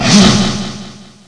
chew.mp3